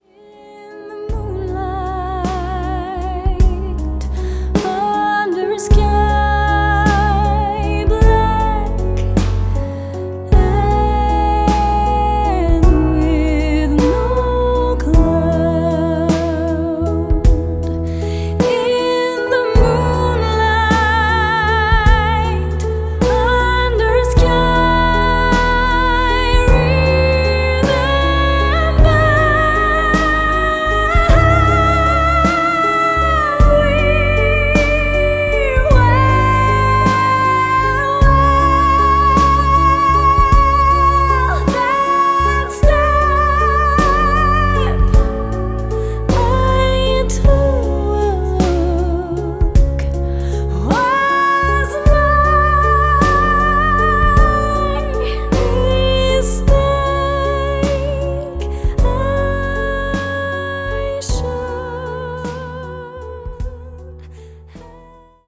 Enregistrement et Mixage